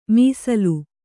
♪ mīsalu